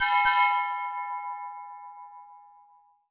auction bell3.wav